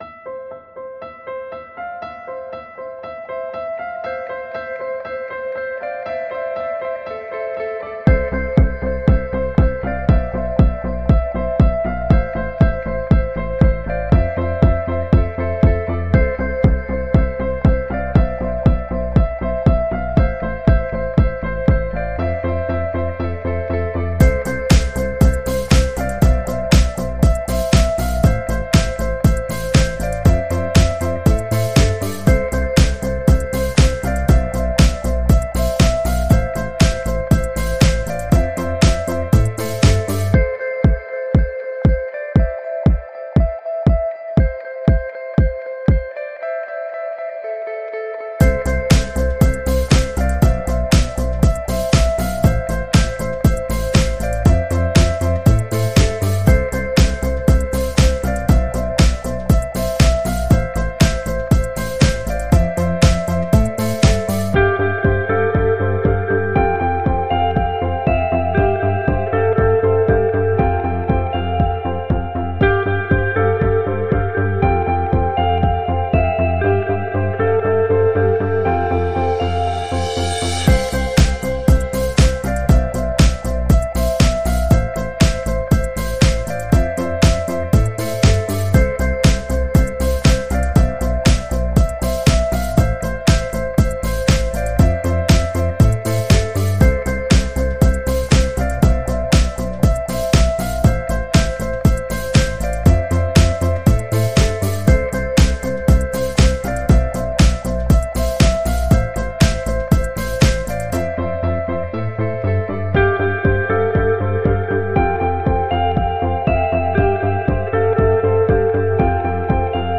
Light electronic pop